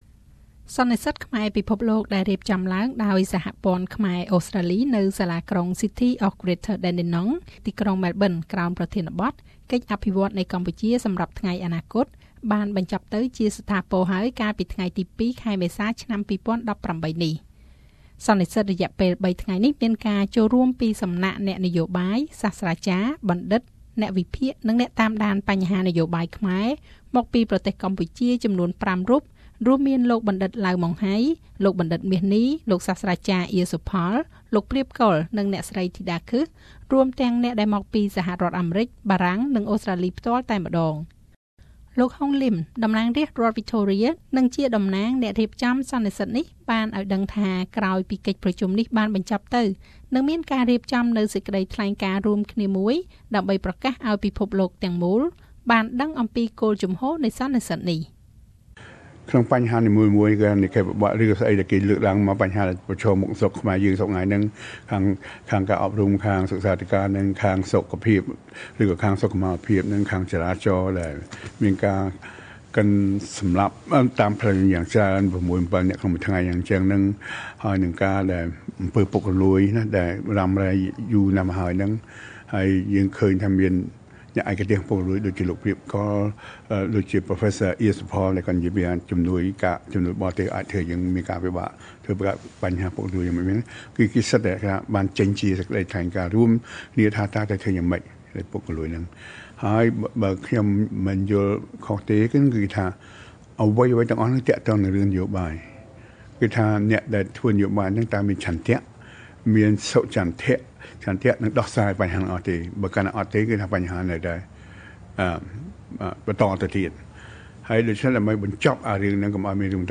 (សំឡេង លោក ហុង លីម)
(សំឡេងអ្នកចូលរួម)